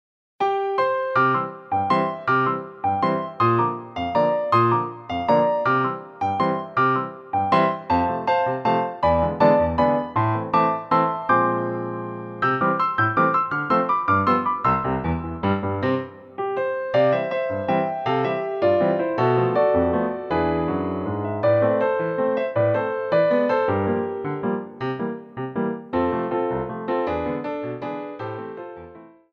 Partitura para Piano Solo 🇲🇽
• 🎼 Tonalidad: Do Mayor (C).